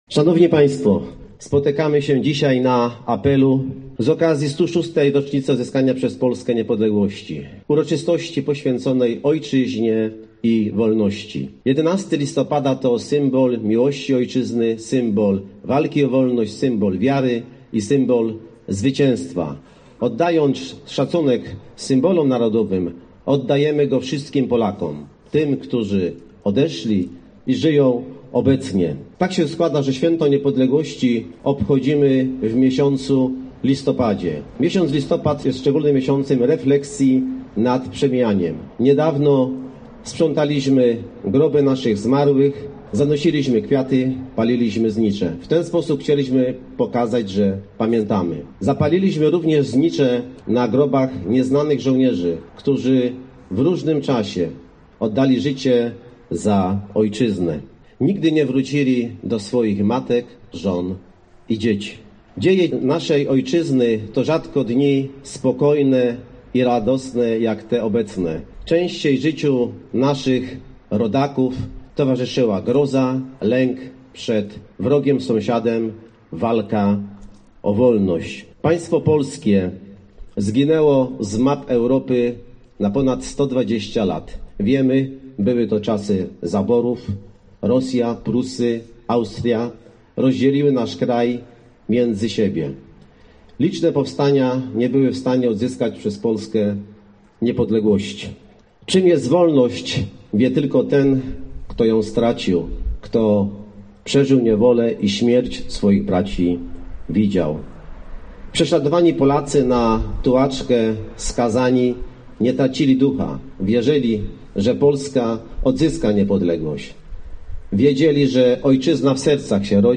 Tak się składa, że święto niepodległości obchodzimy w listopadzie, miesiącu refleksji nad przemijaniem – mówił podczas uroczystości Grzegorz Mielczarek, wójt gminy Biała.